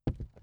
ES_Walk Wood Creaks 3.wav